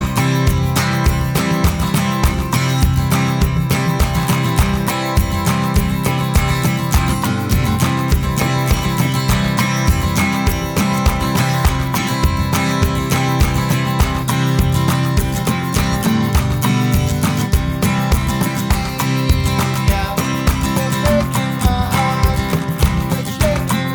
No Harmony Pop (1960s) 2:45 Buy £1.50